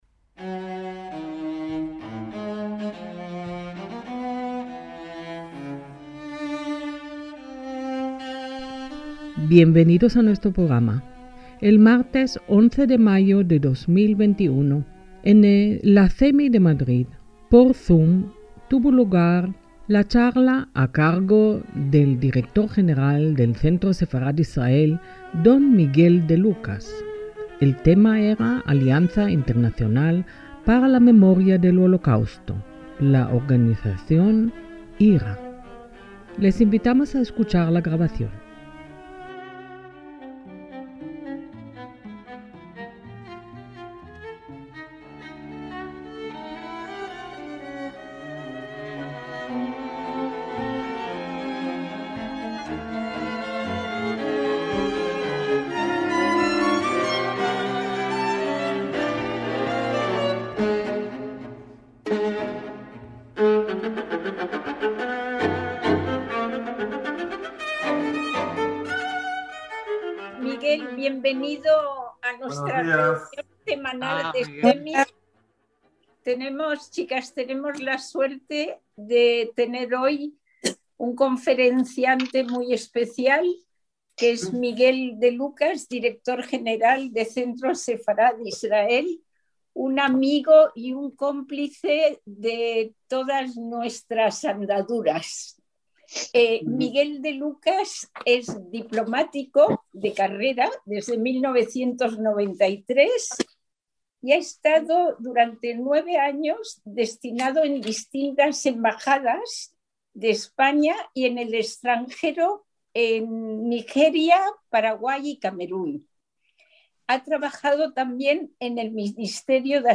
ACTOS "EN DIRECTO" - La International Holocaust Remembrance Alliance ('Alianza Internacional para el Recuerdo del Holocausto'; IHRA, por sus siglas en inglés) es una organización intergubernamental fundada en 1998 que reúne a gobiernos y expertos para fortalecer, avanzar y promover la educación, la investigación y el recuerdo del Holocausto en todo el mundo y para cumplir los compromisos de la Declaración del Foro Internacional de Estocolmo sobre el Holocausto (o «Declaración de Estocolmo»).